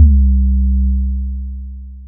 Gutta808-21.wav